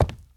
sounds / step / wood5.ogg
wood5.ogg